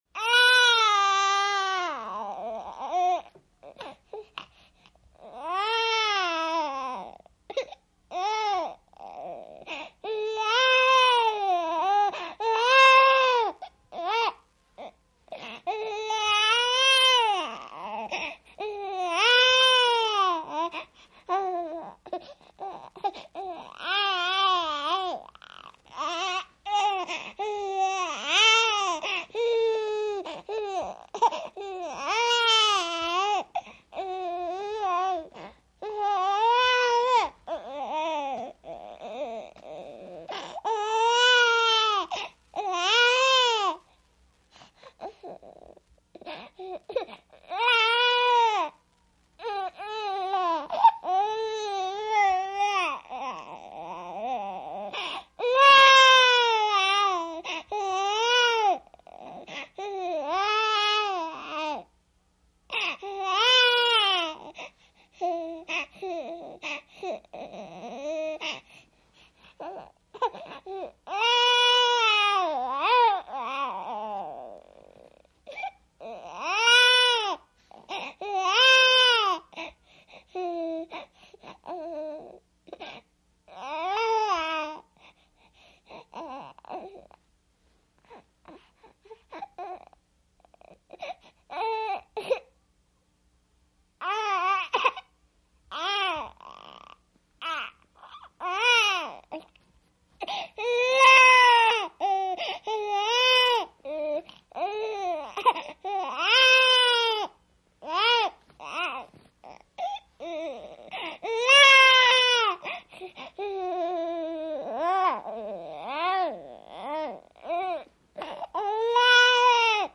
BABY THREE MONTH OLD GIRL: INT: Crying & fretting, desperate gasps.